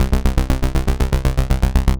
Die folgenden Beispiele basieren auf einem 16tel-Raster.
Sequencer Steps - 16th
Alle 16tel wurden angeschaltet und spielen hier
faq__step_sequencer__16_steps.mp3